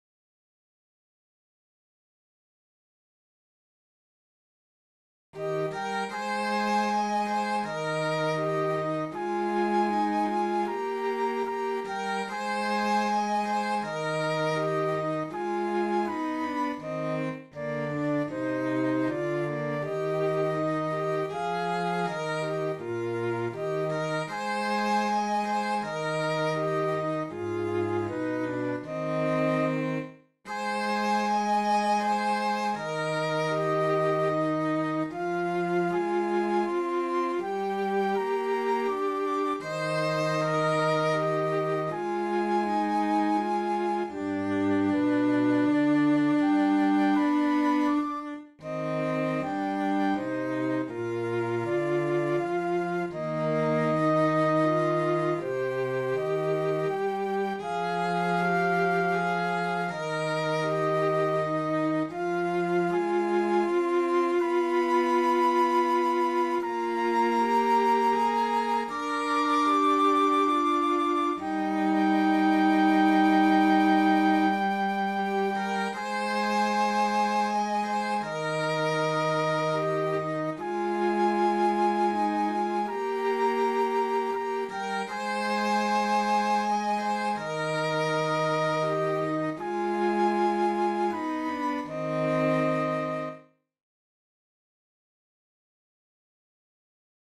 Isan-sydan-huilu-mukana.mp3